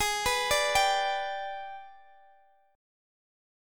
Listen to AbmM7b5 strummed